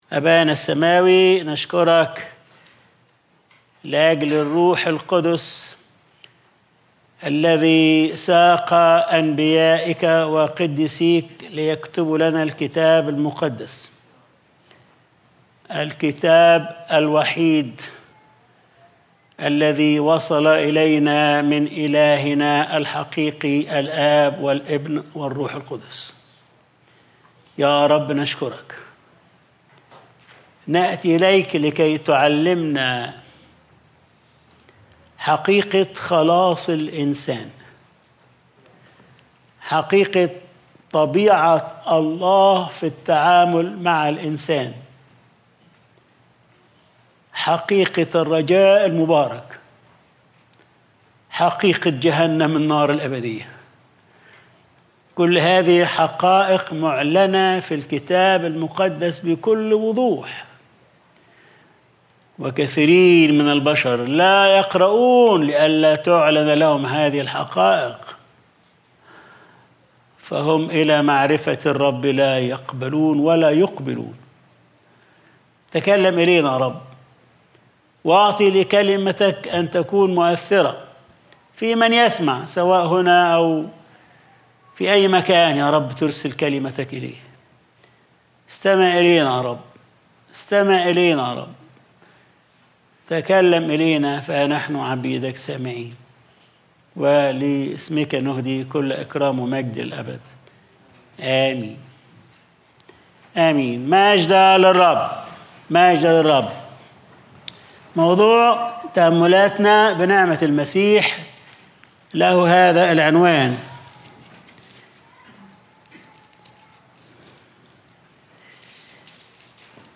Sunday Service | رَسَن مُضِل - تُفتة مُركبَّة - Arabic Bible Christian Church الكنيسة العربية المسيحية الكتابية